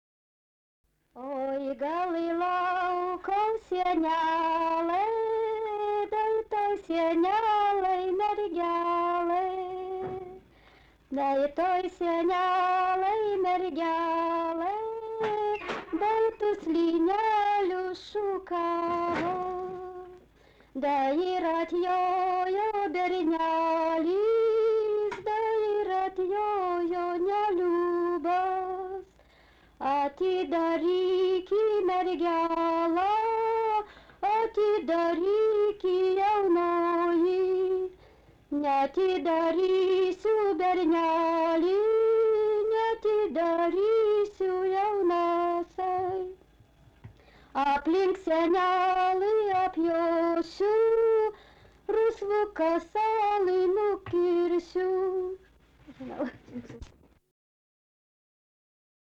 pasaka
Petrikai (Pyetryki), Baltarusija